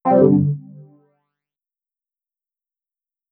eUNEQUIP.wav